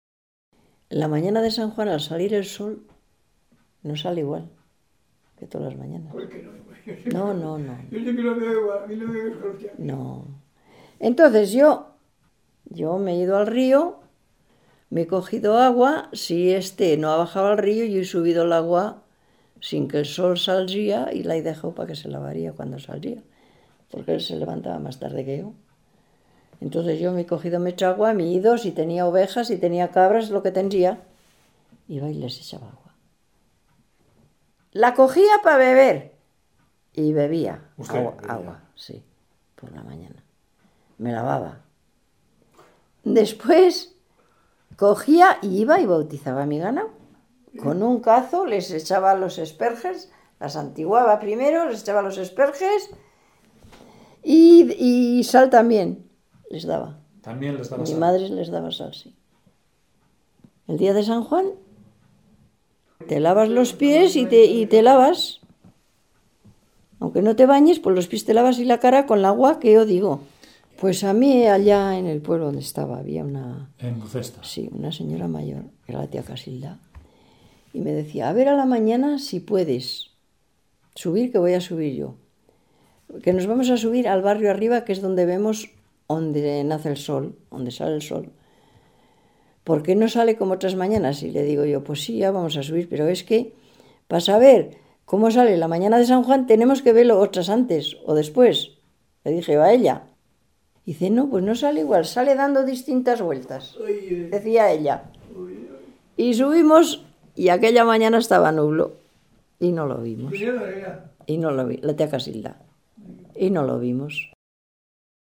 Clasificación: Supersticiones
Lugar y fecha de grabación: Logroño, 2 de mayo de 2002